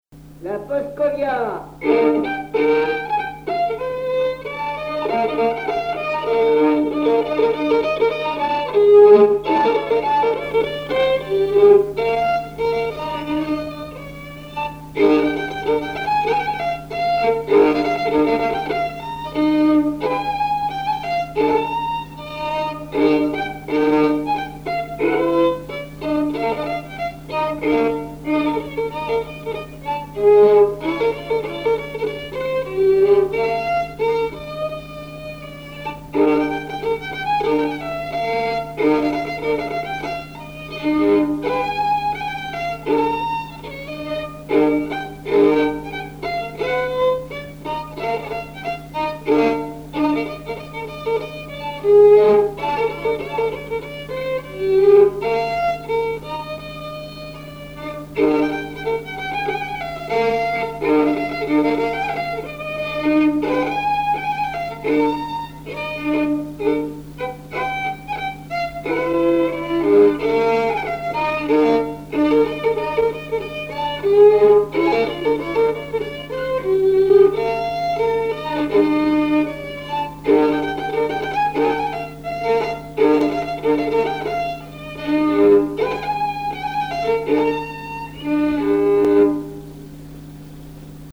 danse : paskovia
Pièce musicale inédite